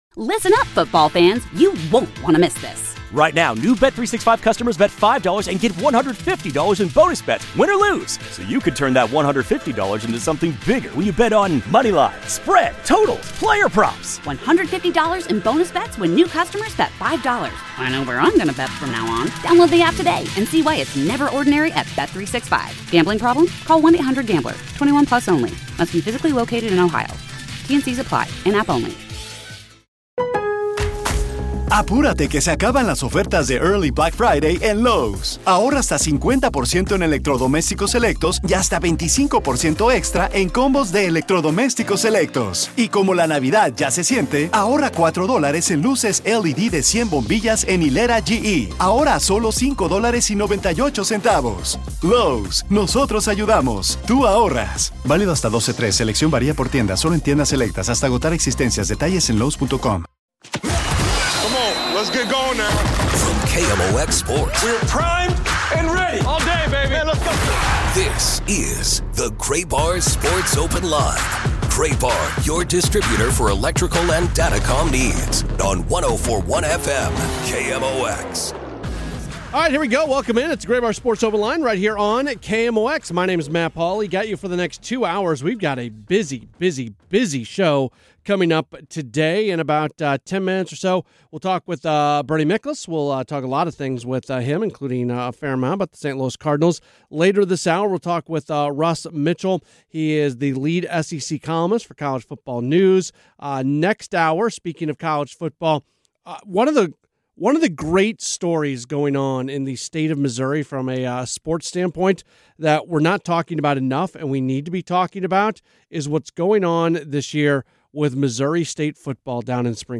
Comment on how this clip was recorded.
The nightly program has been a fixture on KMOX for many years and features a variety of hosts.